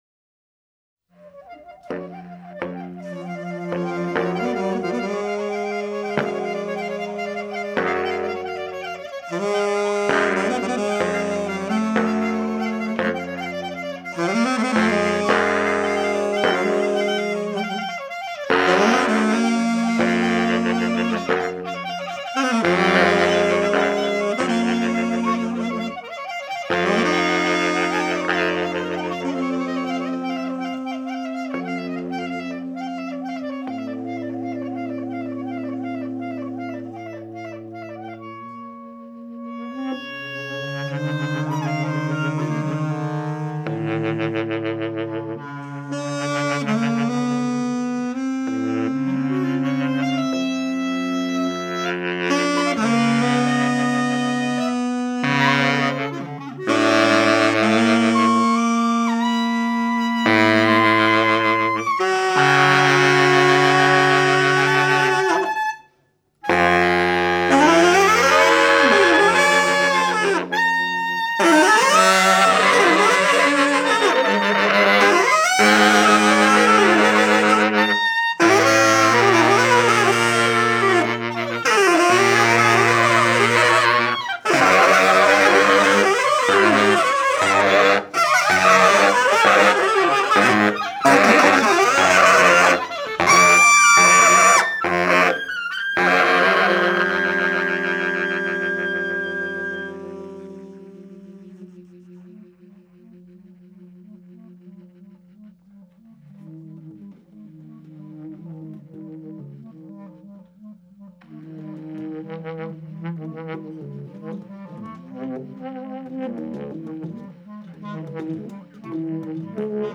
They are the salad bowl of improv!
The result is a question and answer game within the sounds.